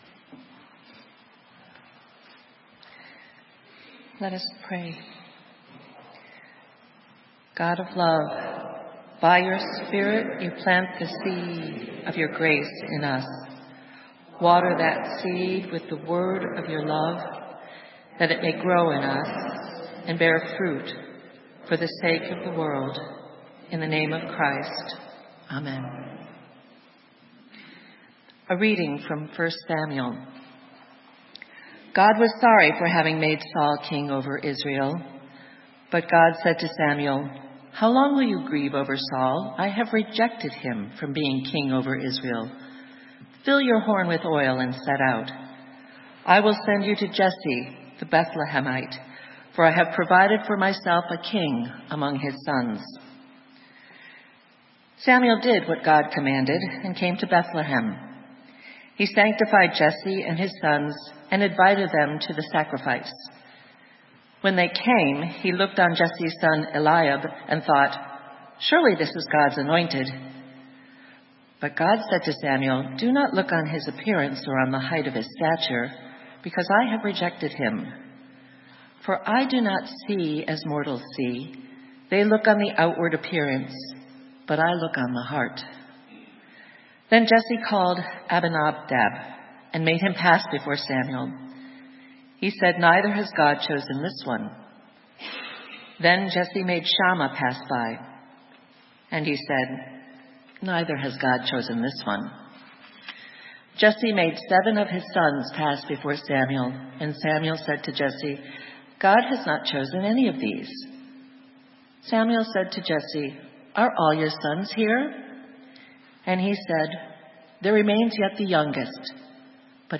Sermon:A seed growing secretly - St. Matthew's UMC